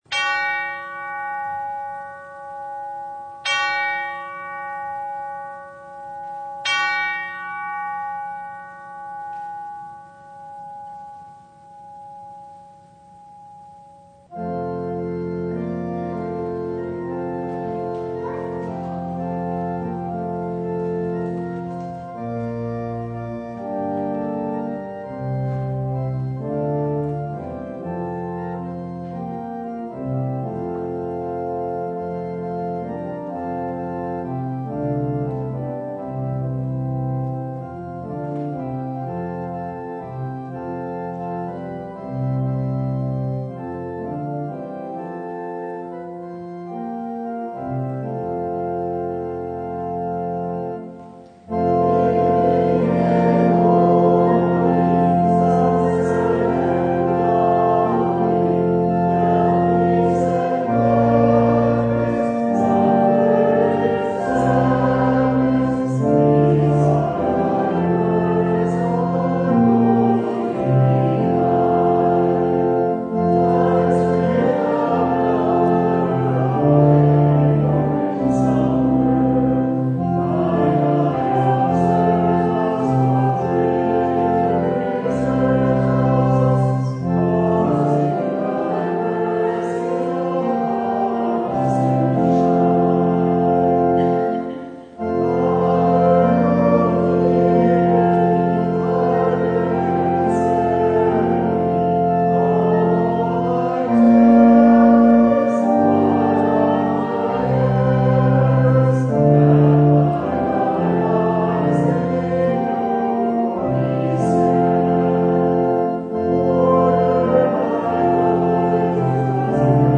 Service Type: Sunday
Download Files Notes Bulletin Topics: Full Service « Jesus and the Harvest Confess or Deny—What Will It Be?